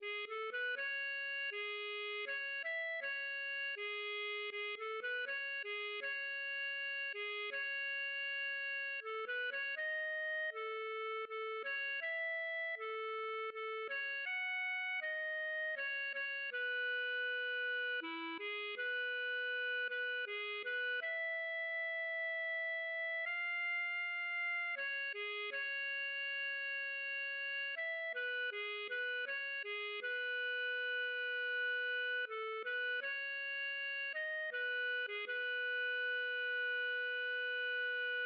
Ein Minnelied, gefunden auf Burg Luringen, Autor unbekannt:
LilyPond 🏰" } myMusic = { << \chords { \germanChords \set chordChanges=##t \set Staff.midiInstrument="acoustic guitar (nylon)" } \relative c'' { \time 3/4 \key e \major \tempo 4=80 \set Staff.midiInstrument="clarinet" \partial 4 \tuplet 3/2 { gis8 a8 b8 }